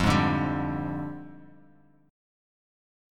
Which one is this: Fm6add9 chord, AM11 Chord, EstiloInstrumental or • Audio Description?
Fm6add9 chord